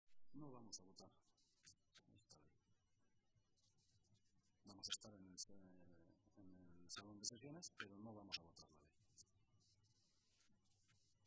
Así lo ha manifestado esta mañana, en rueda de prensa, el portavoz del Grupo Socialista, José Luis Martínez Guijarro.